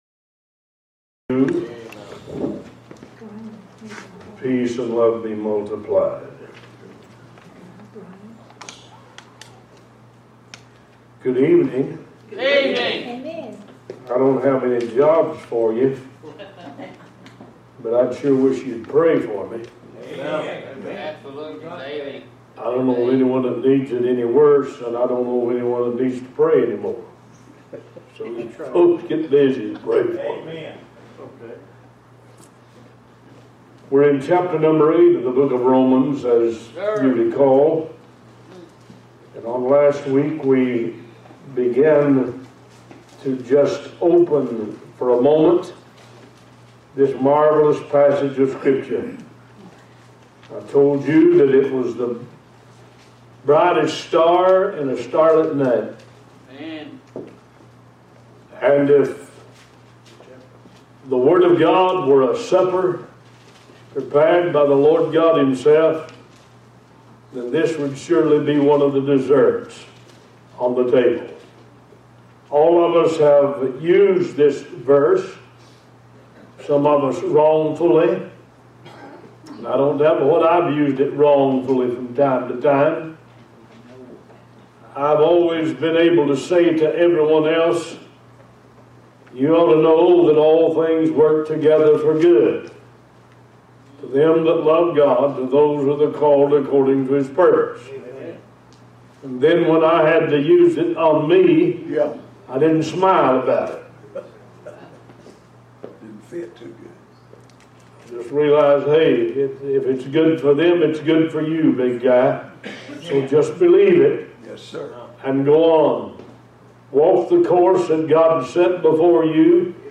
One Voice Talk Show
New Sermons published every Sunday and Wednesday at 11:30 AM EST